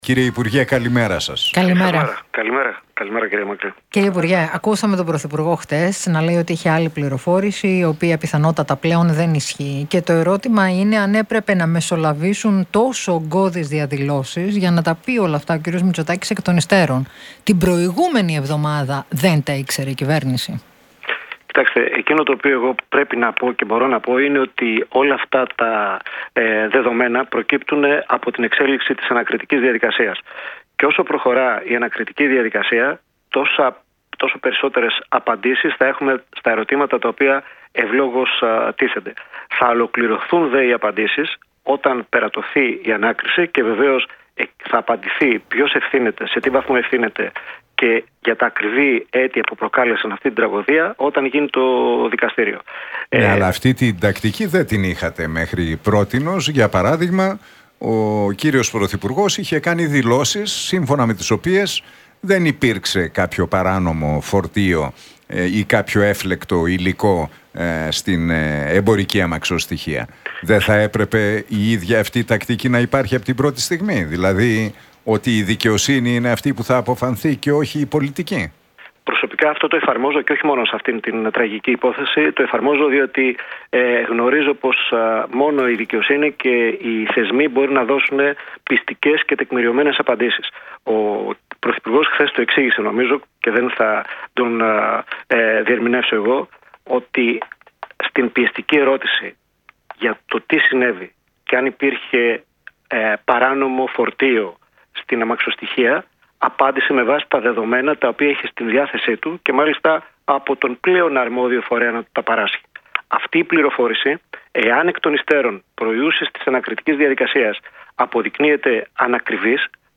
Μπούγας στον Realfm 97,8 για Τέμπη: Μόνο η δικαιοσύνη και οι θεσμοί μπορούν να δώσουν πειστικές και τεκμηριωμένες απαντήσεις